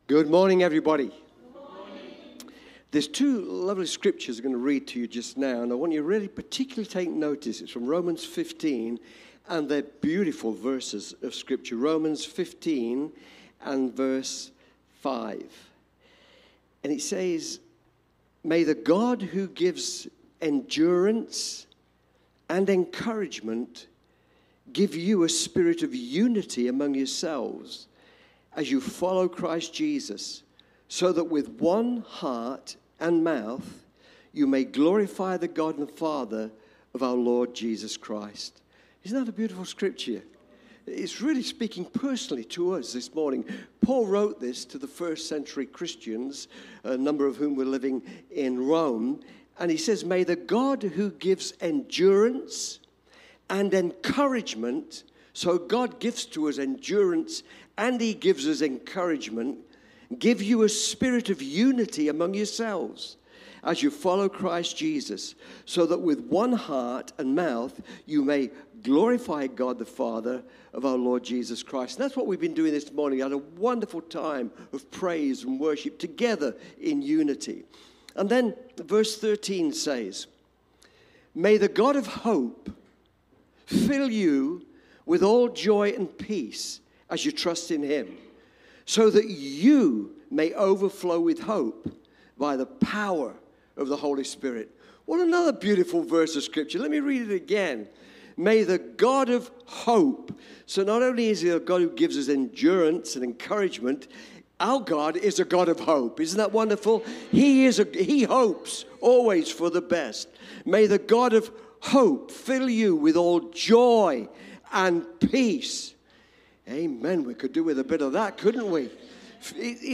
Cityview-Church-Sunday-Service-Disappointment-or-Discouragement.mp3